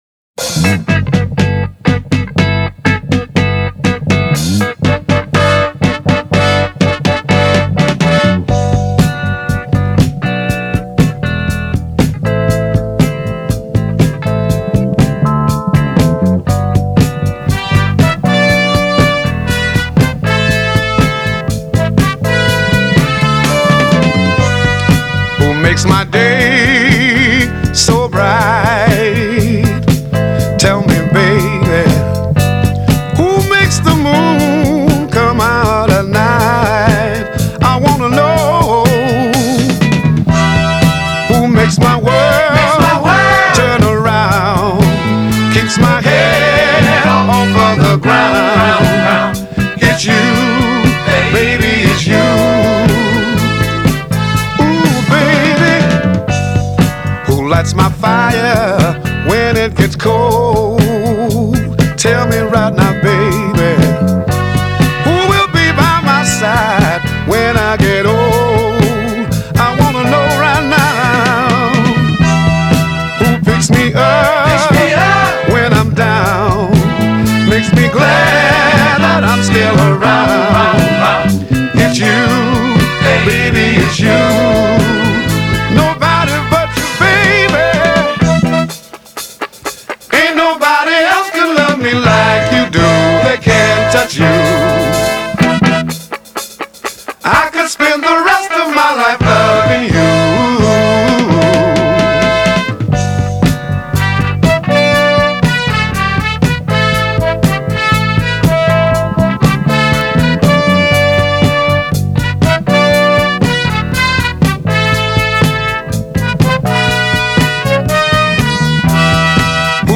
epitomised the soul/blues ballad genre.
a horn-led mid-tempo swayer
This soulful pairing